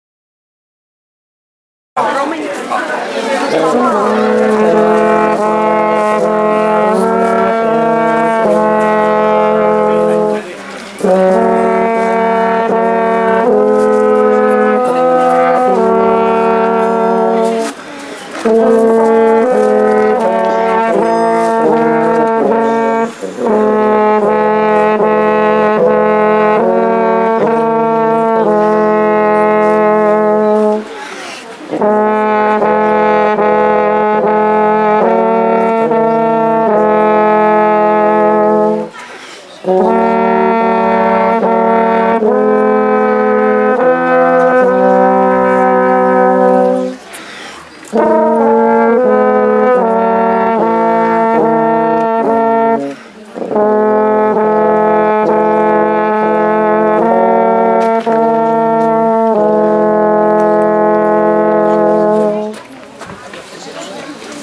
Rozsvícení vánočního stromku v Malém Chlumci – 21.12.2013
Úvodní koleda v podání dvou lesních  rohů
V-pulnocni-hodinu-lesni-rohy.wma